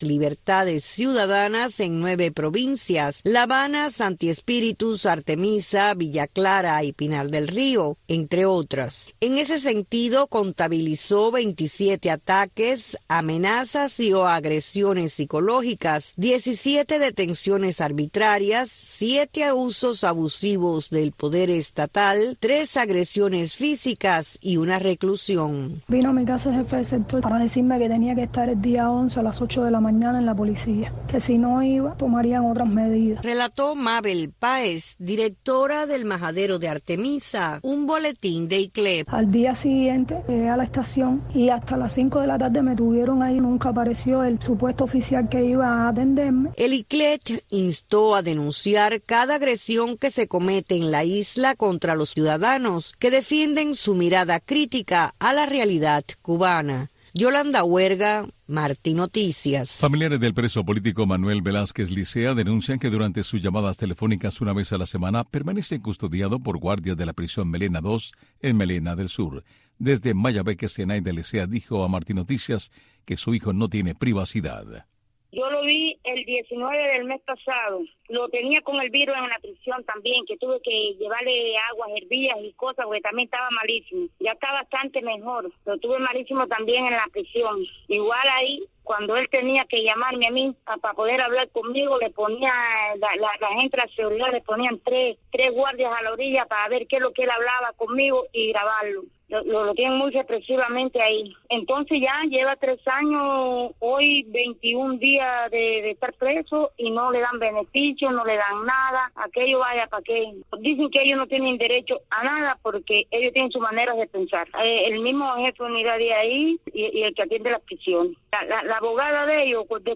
Una mirada a la situación migratoria, para analizar las leyes estadounidenses, conversar con abogados y protagonistas de este andar en busca de libertades y nuevas oportunidades para lograr una migración ordenada y segura.